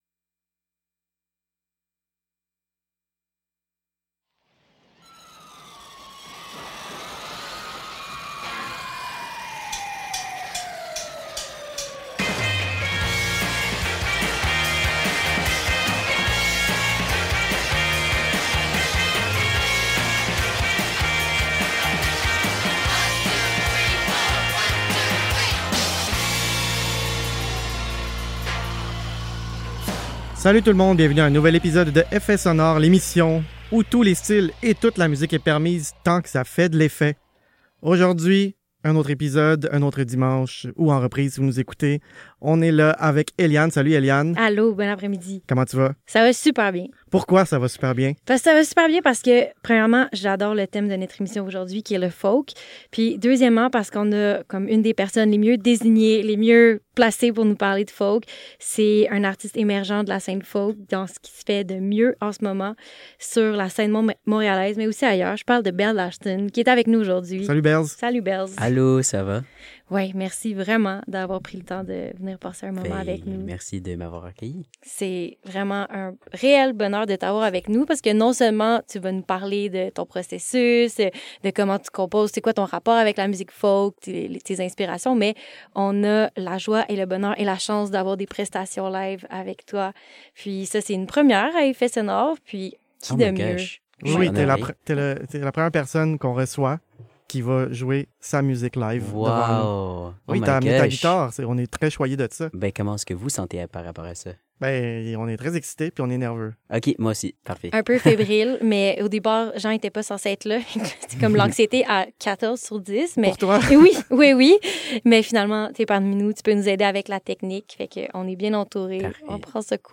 une heure folk envoûtante, ponctuée d’une prestation live intimiste